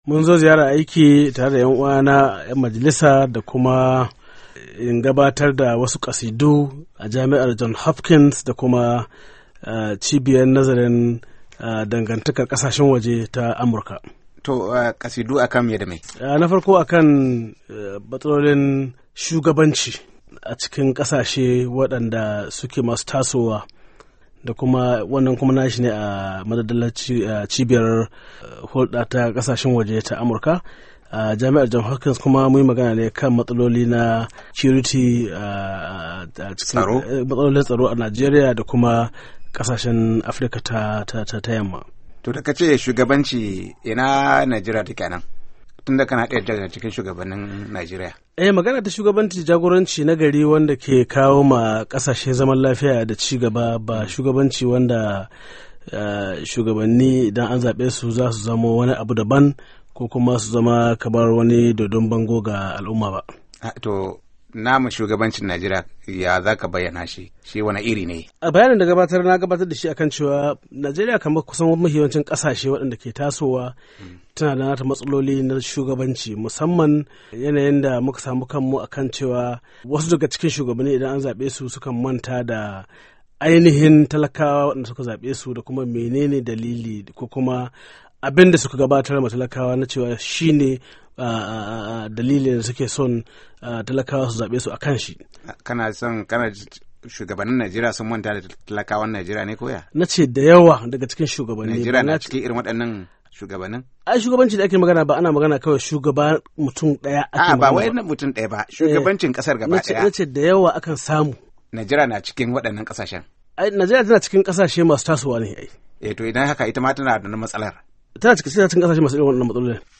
A ziyarar da ya kowawa Muryar Amurka, sashen Hausa ya tattauna da Honorable Aminu Waziri Tambuwal, kakakin Majalisar Wakilai ta Najeriya akan zuwanshi nan Amurka, da matsalolin jam’iyyar PDP.